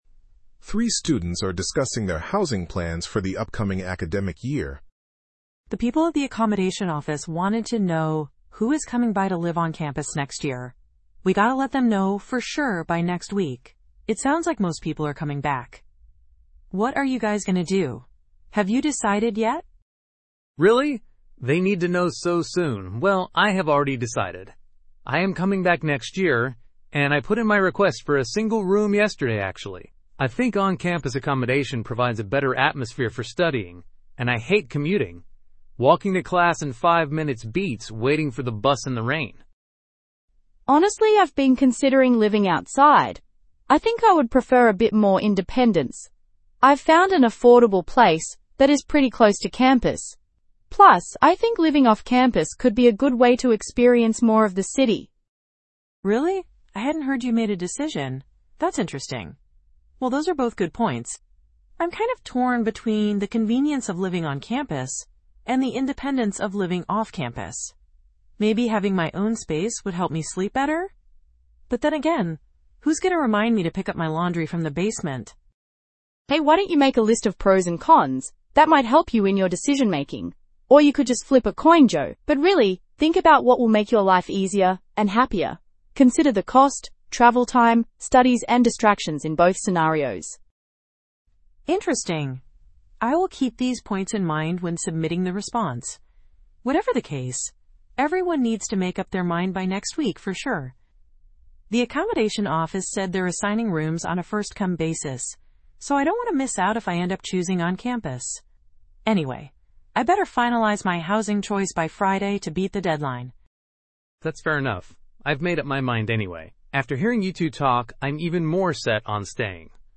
PTE Summarize Group Discussion – Housing Plans